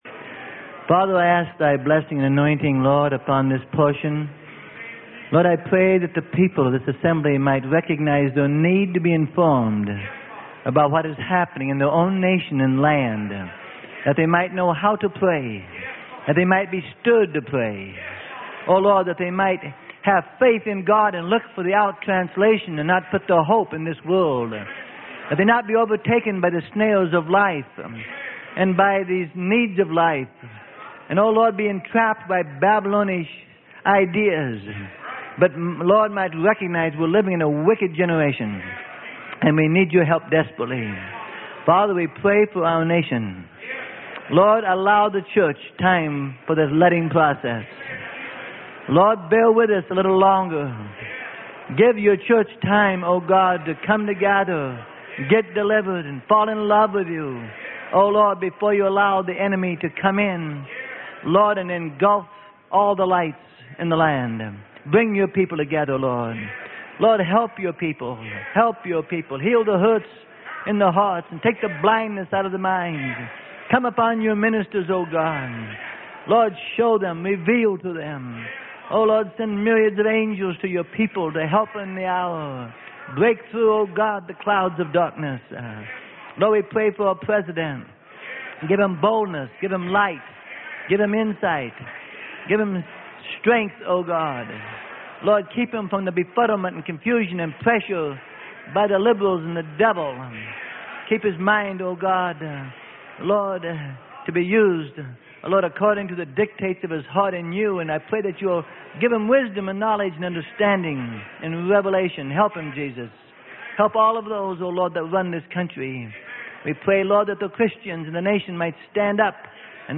Sermon: 1984 STATE OF THE UNION ADDRESS - PART 2 OF 4 - Freely Given Online Library